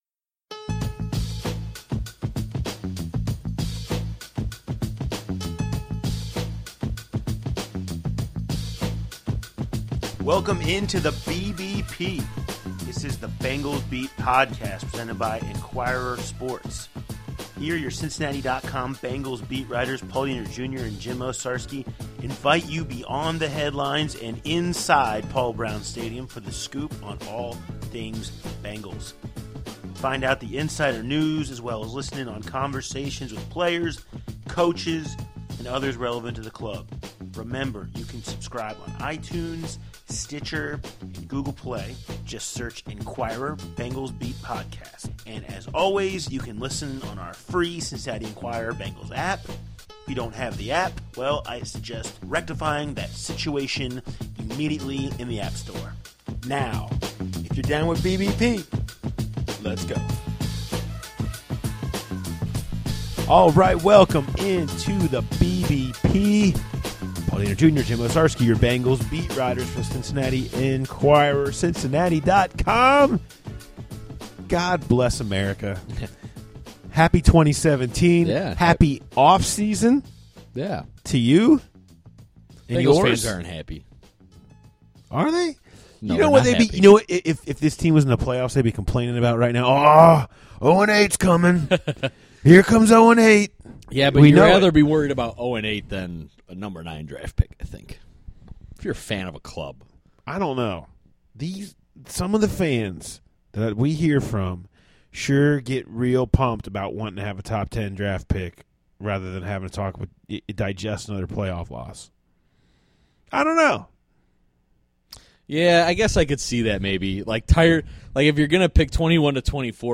Hear from AJ McCarron about potentially being traded, Tyler Eifert on yet another season of rehab and A.J. Green on what went wrong in Houston.